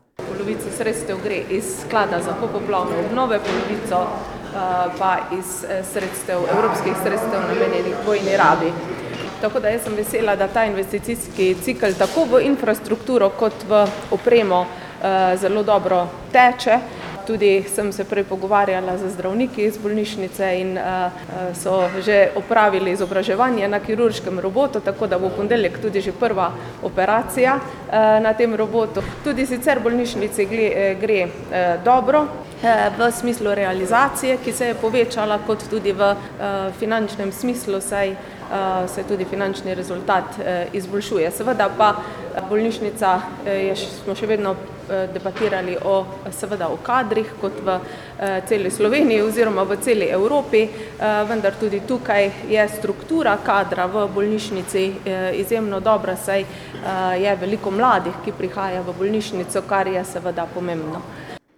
izjava ministrica.mp3